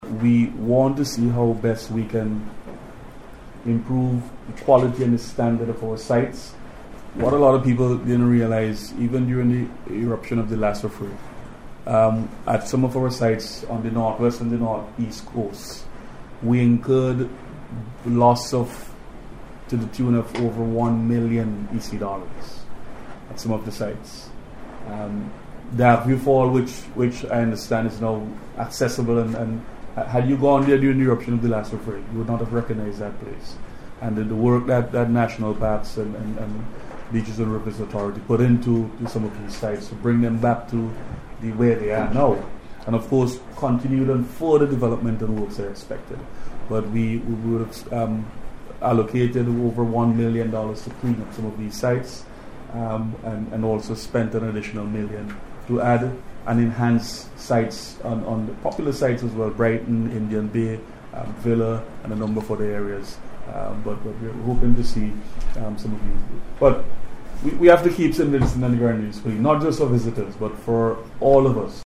Meanwhile , Minister of Tourism, Carlos James says a significant amount of money has been allocated by the Government to improve the tourism sites throughout the country.